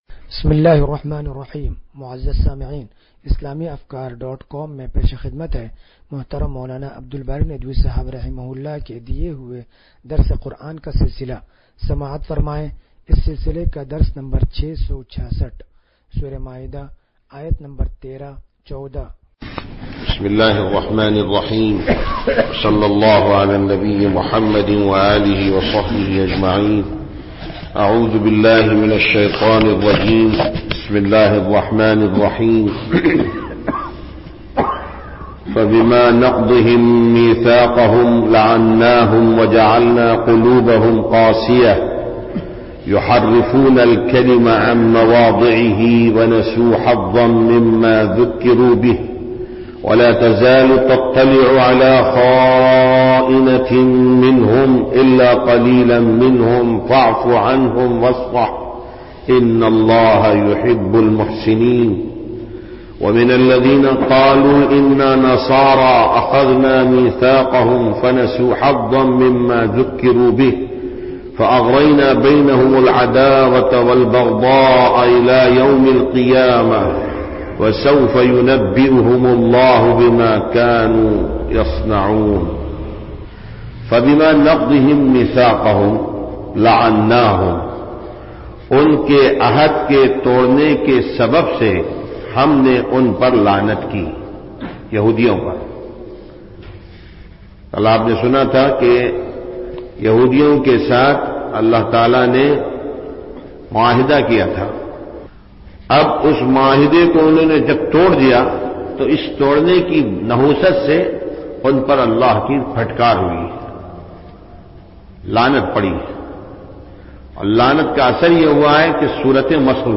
درس قرآن نمبر 0666